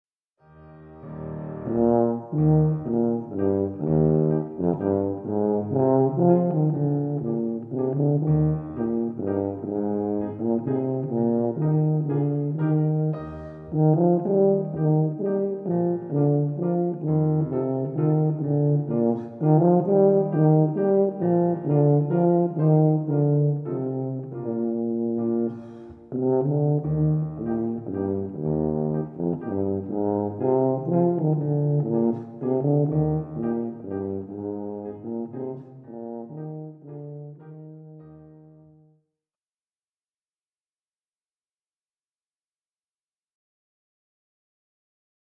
Blechblasinstrumente
Die Basstuba ist das grösste und tiefste Blechblasinstrument.
Tonbeispiel Tuba:
30-Tuba.mp3